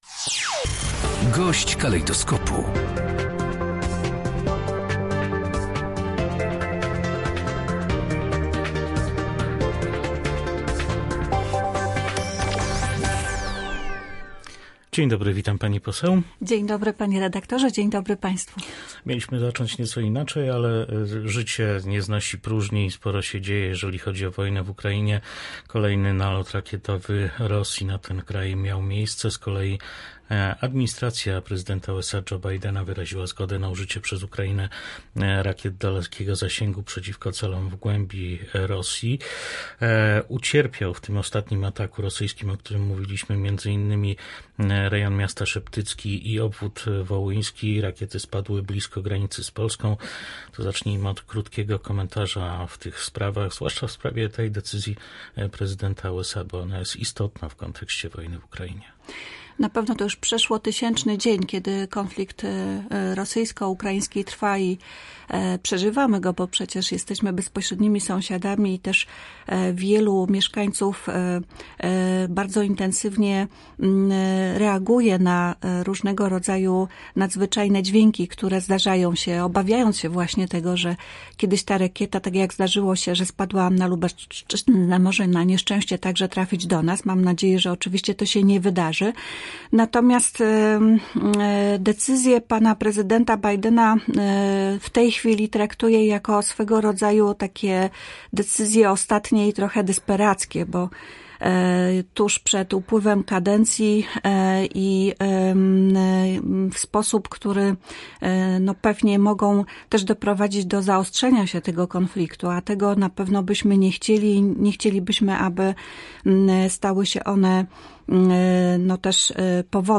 -Prezydent Joe Biden miał ponad dwa lata na zezwolenie Ukrainie na użycie amerykańskich rakiet dalekiego zasięgu. Dlatego pozytywną decyzję w tej sprawie można określić jako desperacką – komentuje działania prezydenta USA posłanka PiS Ewa Leniart.